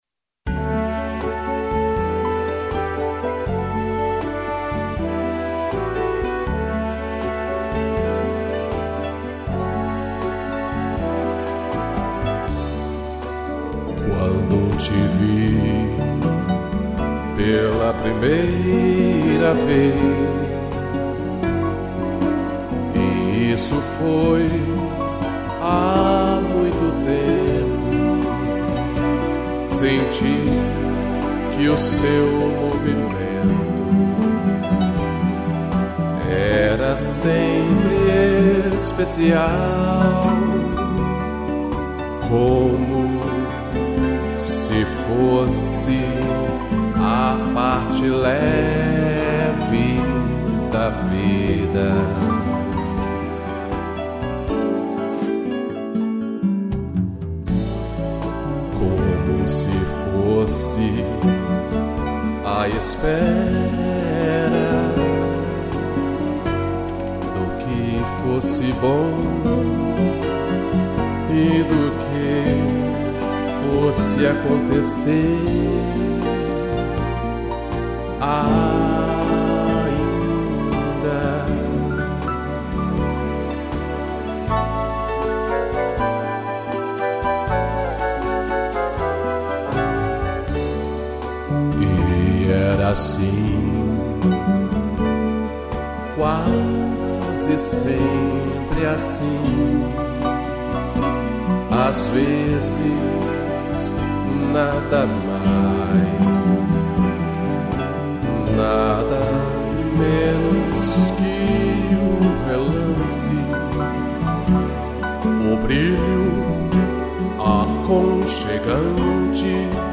voz e violão